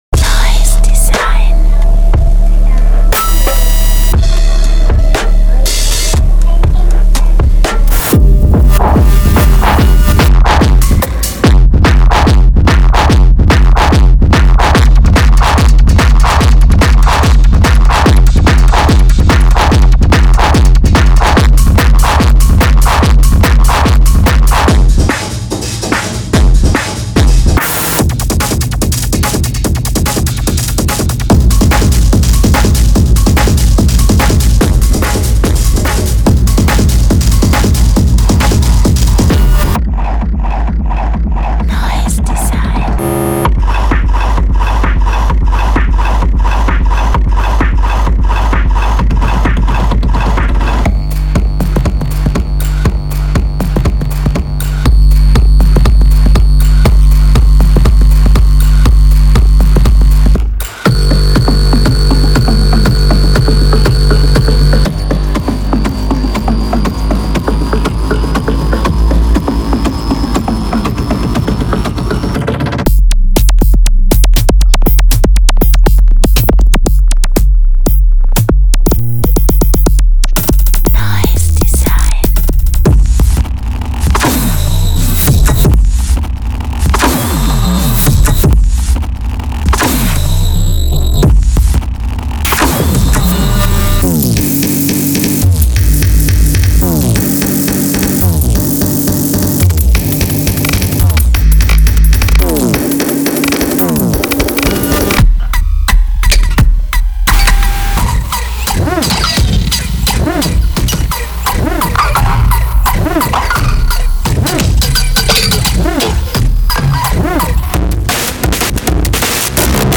IDM系サンプルパックをご紹介いたします。
・87種類のフルドラムループ
・13種類のベースループ